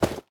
Minecraft Version Minecraft Version 25w18a Latest Release | Latest Snapshot 25w18a / assets / minecraft / sounds / item / armor / equip_generic6.ogg Compare With Compare With Latest Release | Latest Snapshot
equip_generic6.ogg